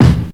Kick_13.wav